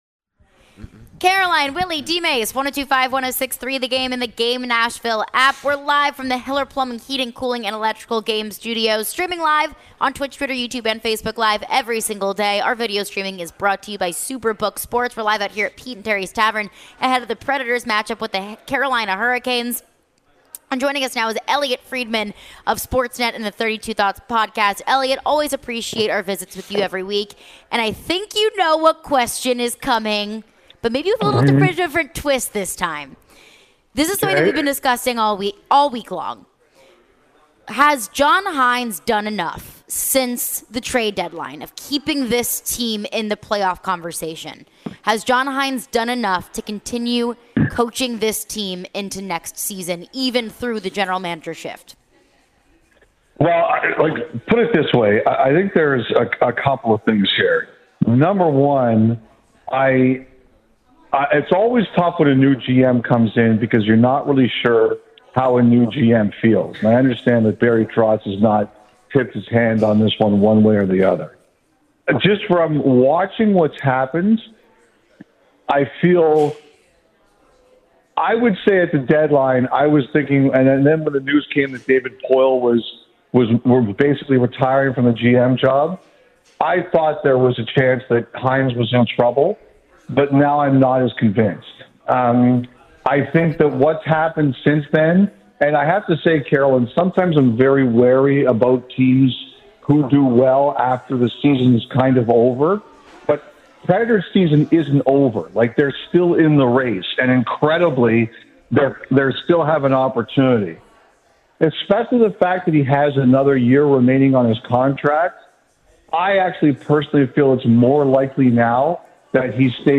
Elliotte Friedman Interview (4-6-23)